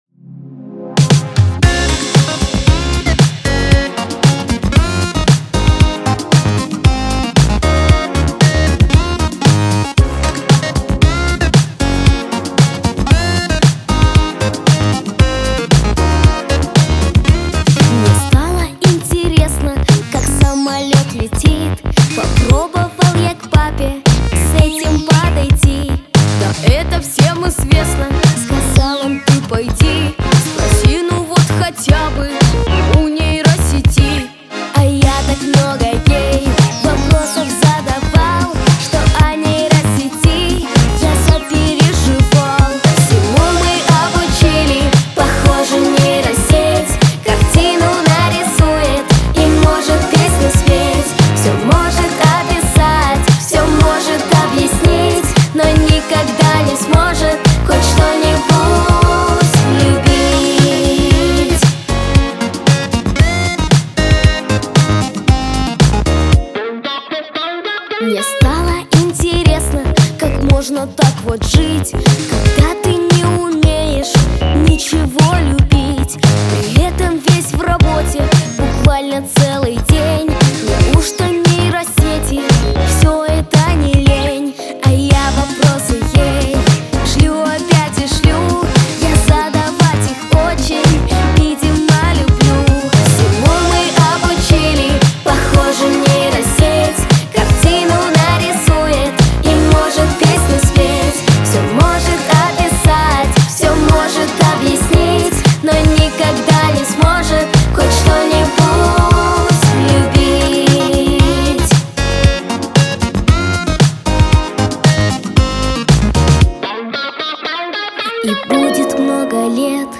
Веселая музыка , танцы